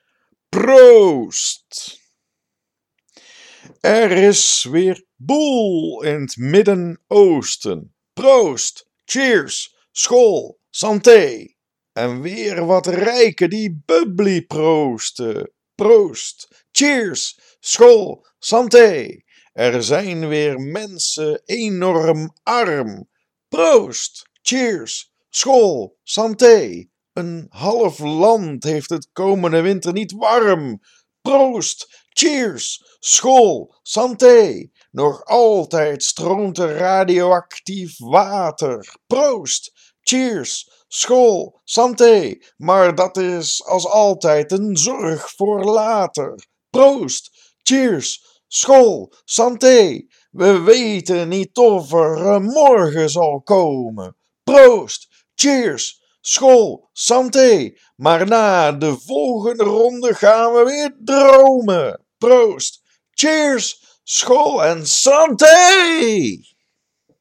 Poëzie